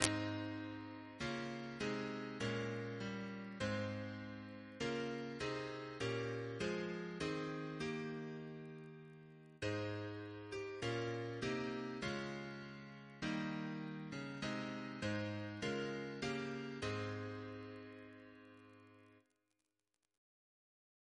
Double chant in A♭ Composer: Gerald Knight (1908-1979), Organist of Canterbury Cathedral, Director of the RSCM Reference psalters: ACB: 243; ACP: 64; CWP: 117; RSCM: 84